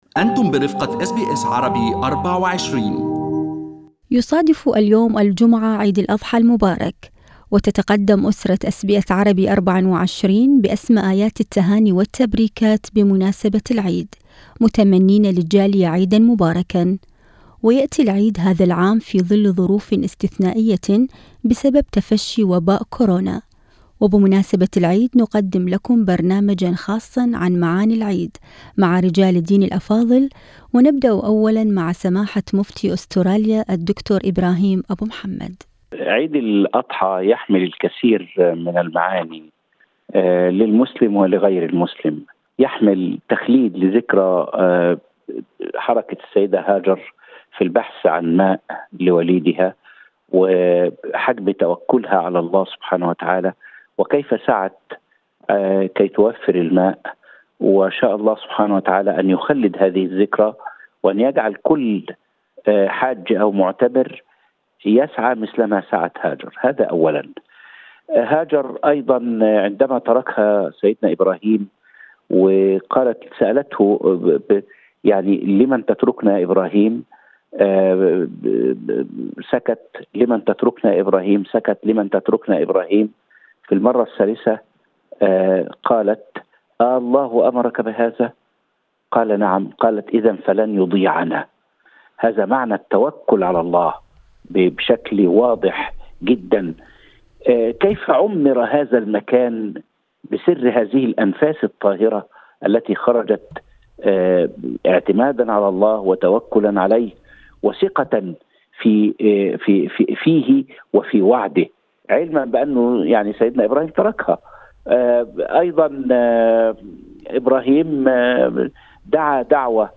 بمناسبة عيد الأضحى المبارك، أعددنا تقريراً خاصاً مع رجال الدين عن المعاني التي يحملها العيد وضرورة إلتزام أبناء الجالية بالإجراءات الوقائية وقواعد التباعد الأجتماعي للحد من تفشي وباء كورونا.